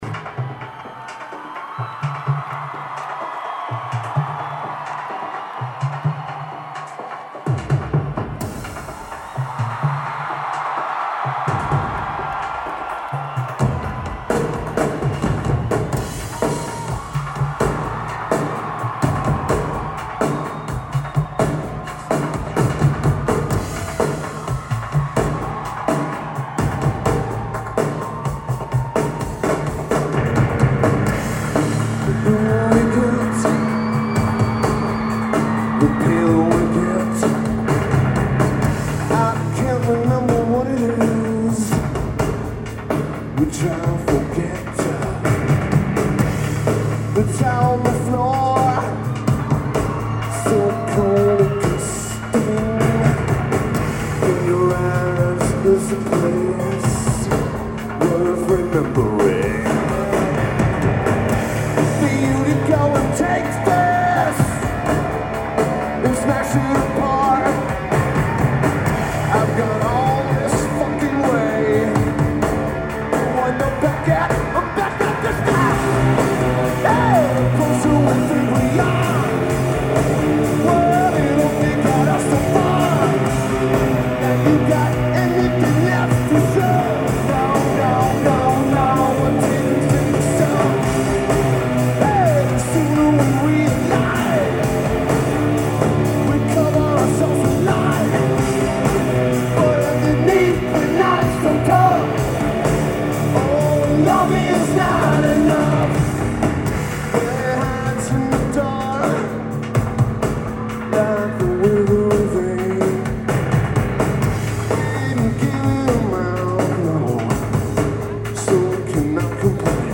Lineage: Audio - AUD (CSBs + BB + Sharp MD-MT161)
This is the first show of the With_Teeth Fall Arena Tour.